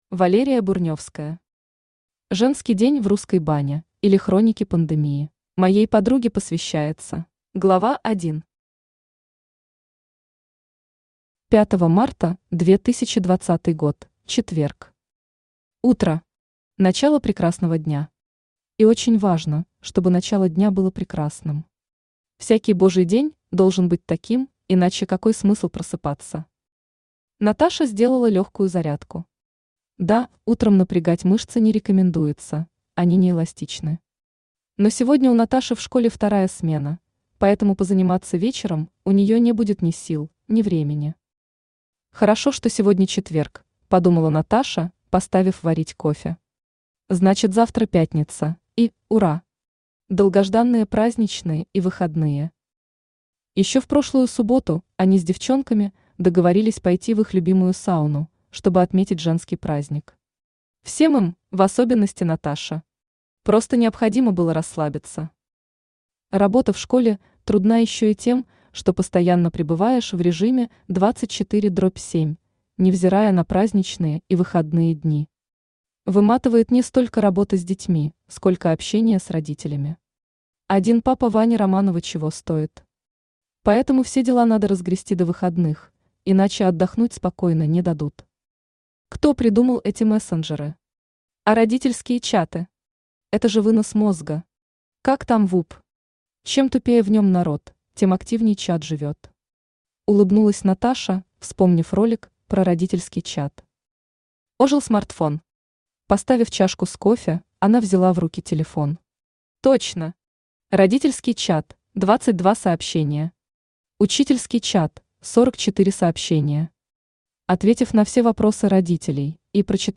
Аудиокнига Женский день в русской бане, или Хроники пандемии | Библиотека аудиокниг